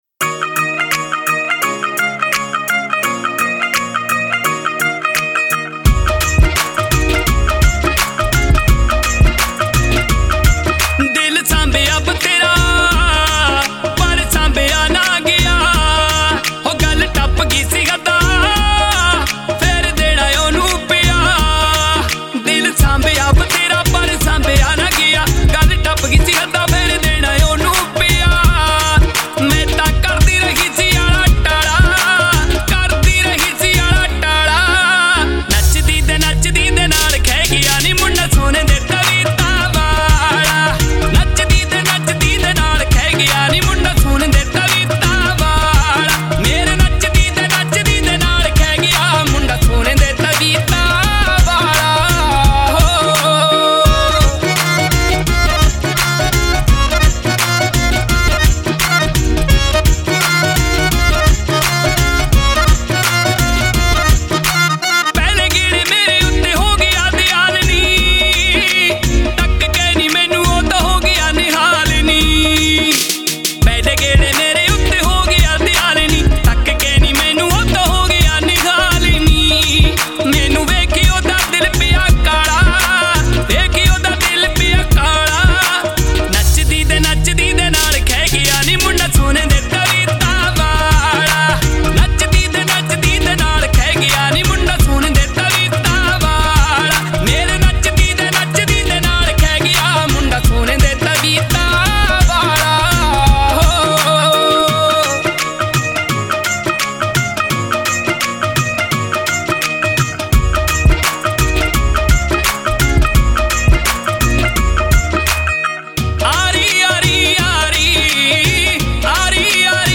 Dj Song Punjabi